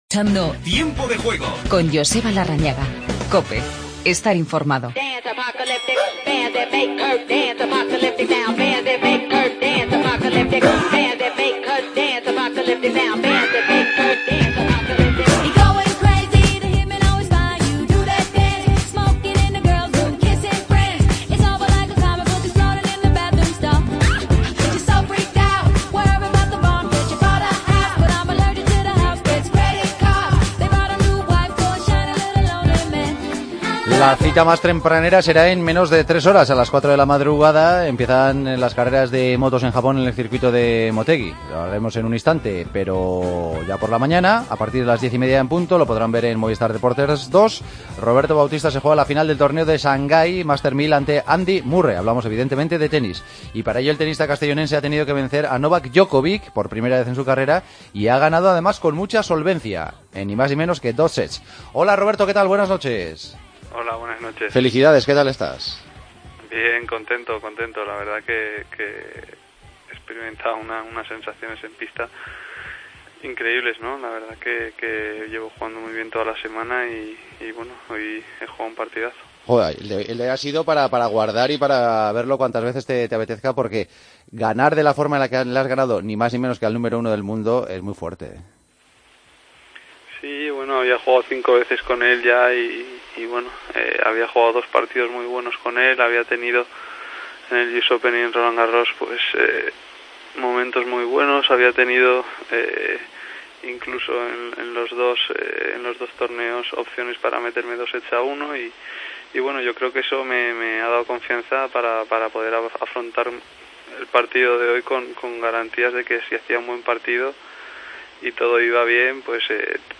Redacción digital Madrid - Publicado el 16 oct 2016, 02:02 - Actualizado 14 mar 2023, 03:05 1 min lectura Descargar Facebook Twitter Whatsapp Telegram Enviar por email Copiar enlace Entrevista a Roberto Bautista, que jugará la final del Torneo de Shangai tras eliminar a Djokovic. Última hora del GP de motociclismo de Japón.